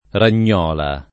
DOP: Dizionario di Ortografia e Pronunzia della lingua italiana
ragnola [ ran’n’ 0 la ]